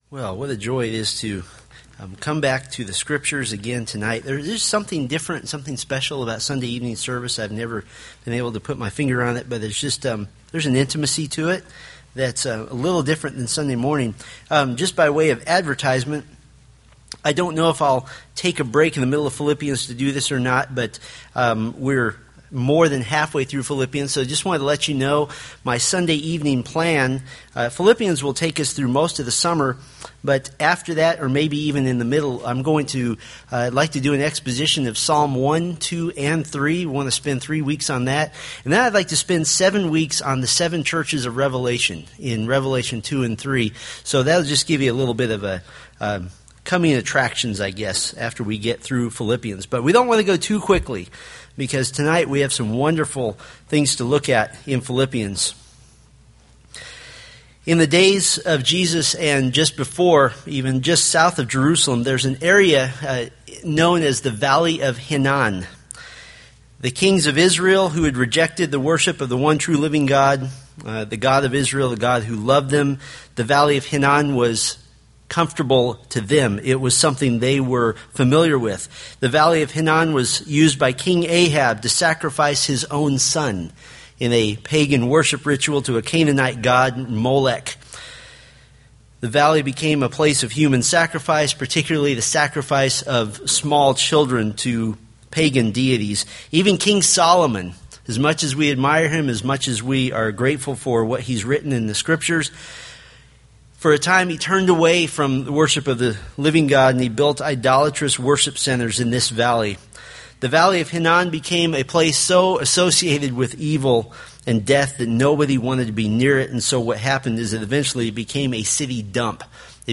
Philippians Sermon Series